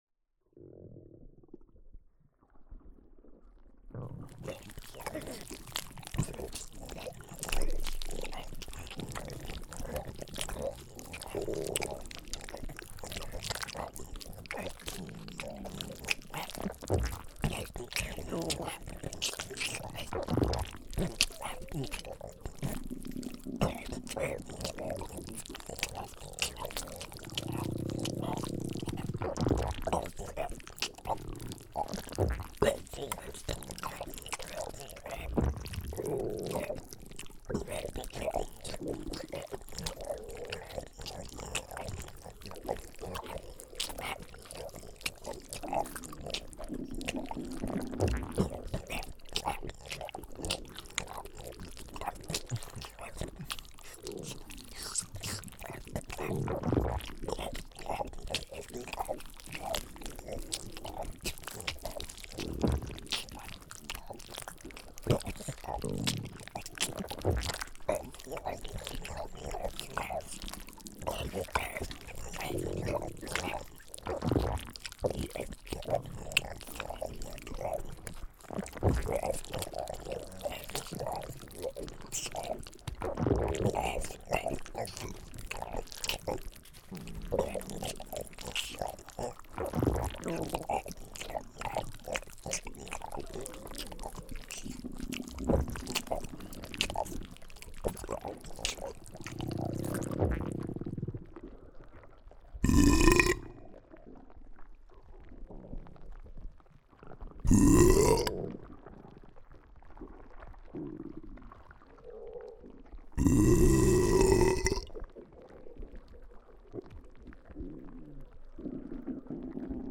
This is a compressed, lower-quality version;
*As always, this audio is better experienced with headphones*
eat stuffing swallow gulp gurgle burp belch stomach noises